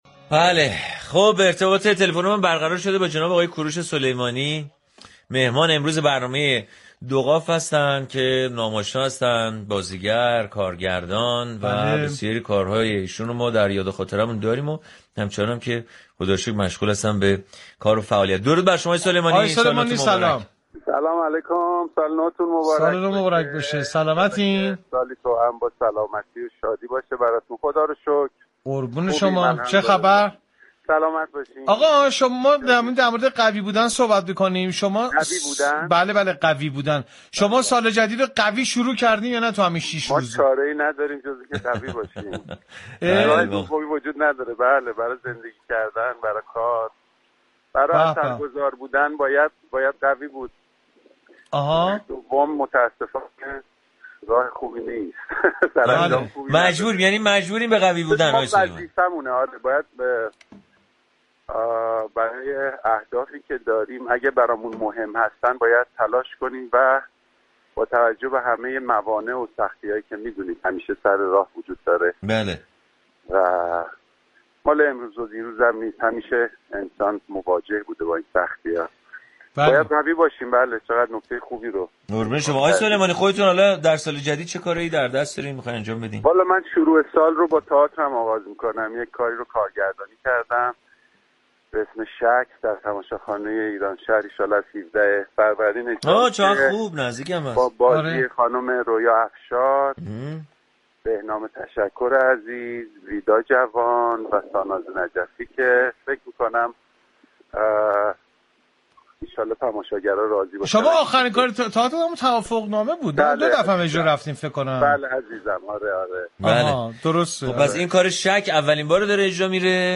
گفتگوی صمیمی با كوروش سلیمانی در برنامه «دو قاف» رادیو صبا
گفتگوی صمیمی و پرانرژی با سلیمانی نشان‌دهنده عشق و تعهد او به هنر و تئاتر بود.